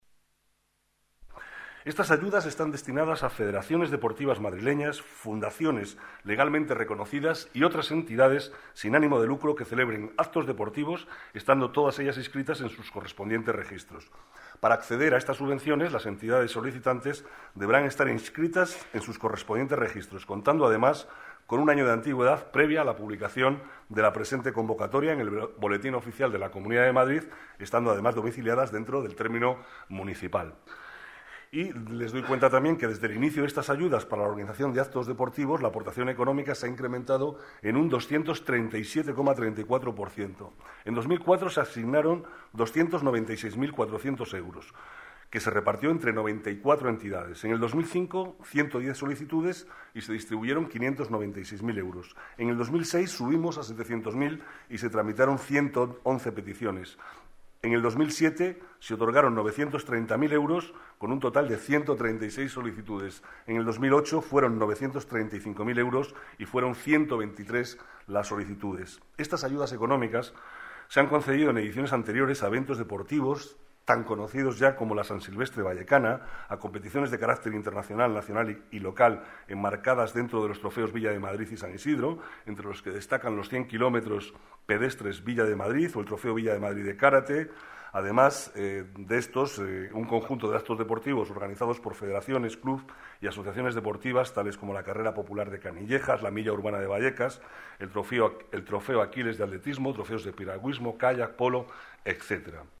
Nueva ventana:Declaraciones del vicealcalde Manuel Cobo sobre subvenciones al deporte